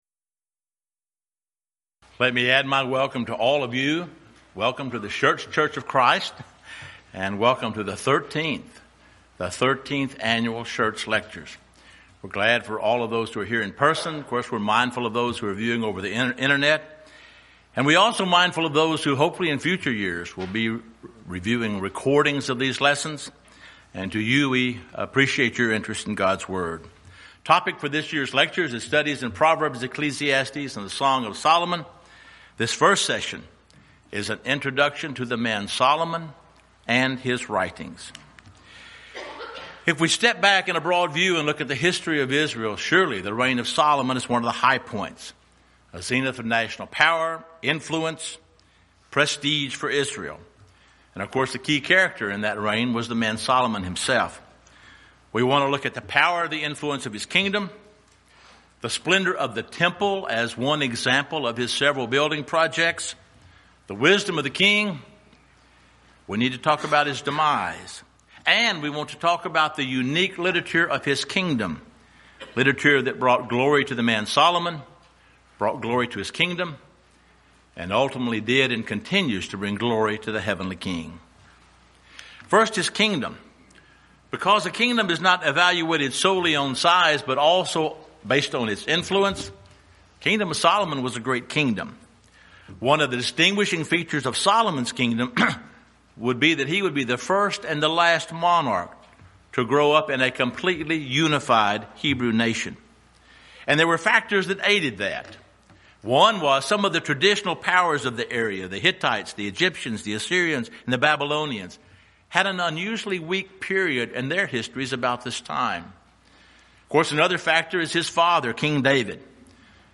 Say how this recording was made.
Series: Schertz Lectureship Event: 13th Annual Schertz Lectures